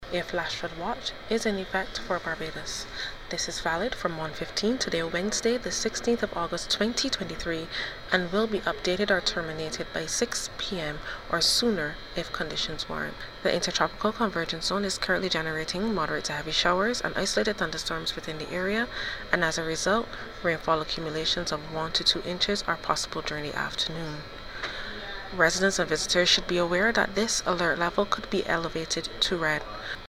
weather update